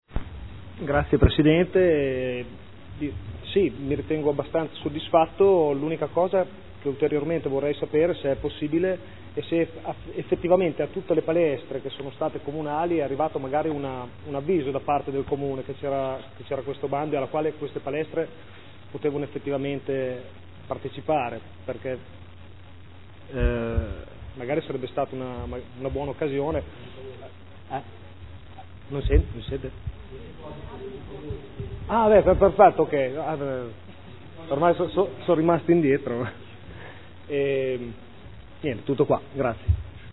Fabrizio Cavani — Sito Audio Consiglio Comunale